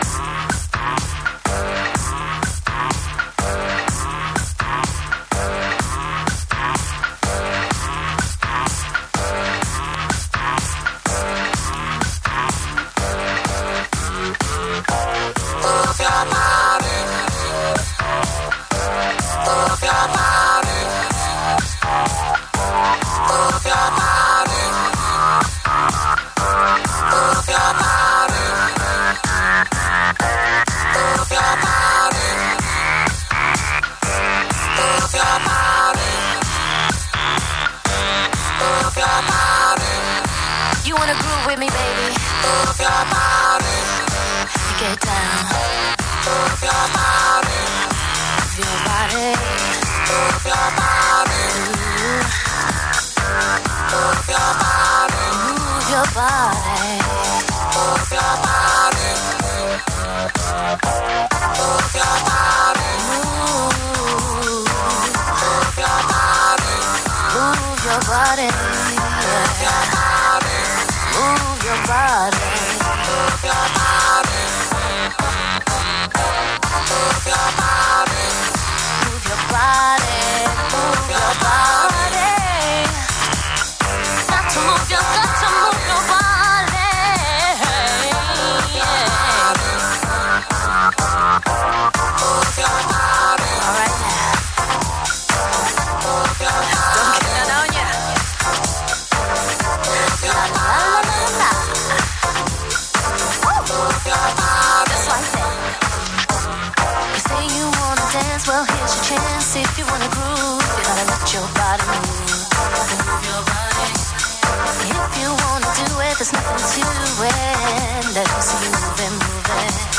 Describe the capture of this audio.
A 2 hours mix I found on an old tape.